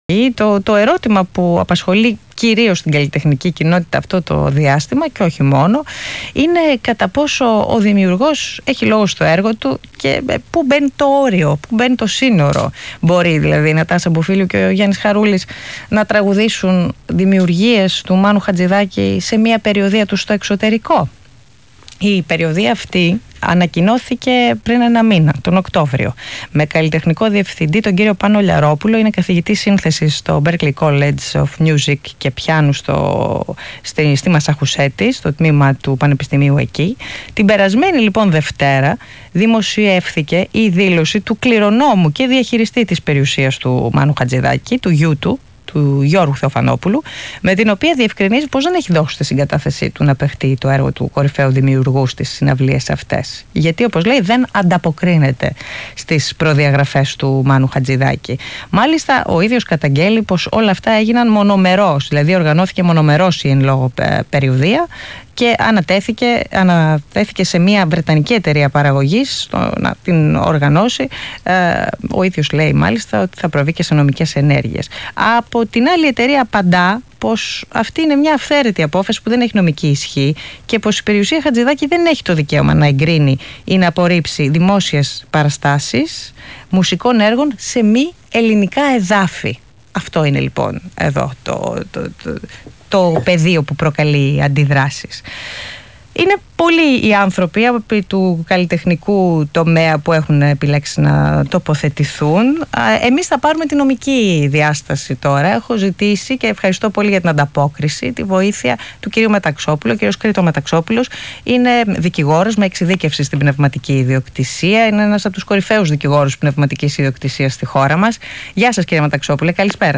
ΣΥΝΕΝΤΕΥΞΗ
ΣΤΟΝ ΡΑΔΙΟΦΩΝΙΚΟ ΣΤΑΘΜΟ ΣΚΑΪ